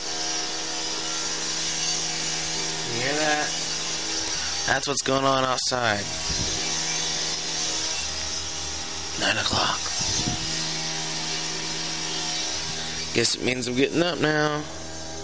loud ass construction outside my window..